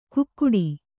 ♪ kukkuḍi